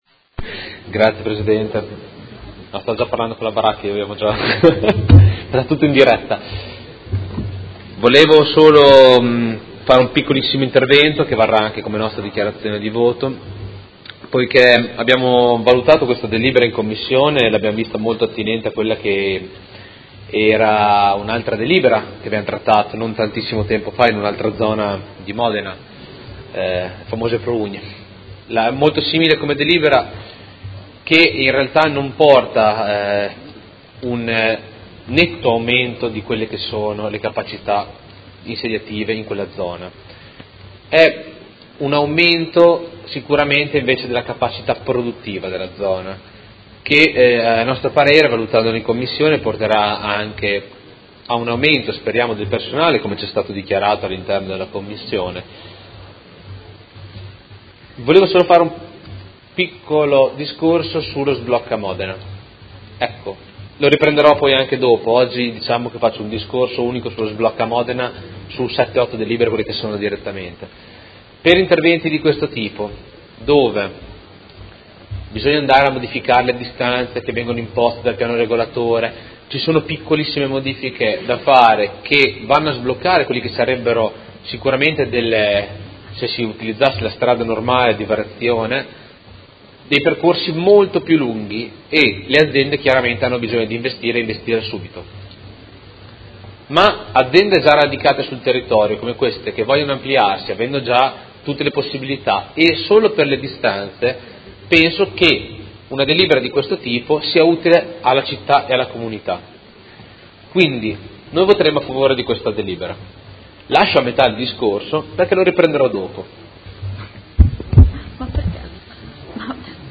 Seduta del 13/07/2017 Dibattito.